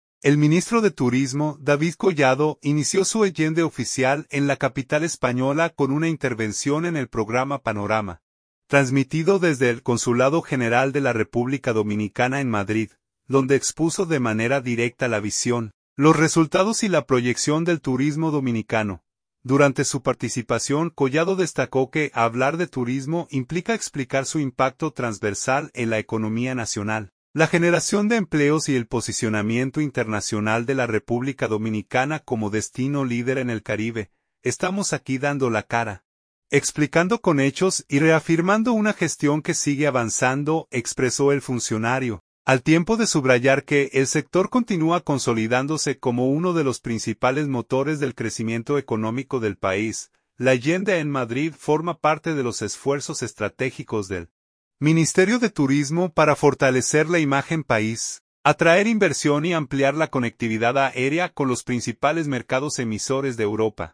Madrid, España. — El ministro de Turismo, David Collado, inició su agenda oficial en la capital española con una intervención en el programa Panorama, transmitido desde el Consulado General de la República Dominicana en Madrid, donde expuso de manera directa la visión, los resultados y la proyección del turismo dominicano.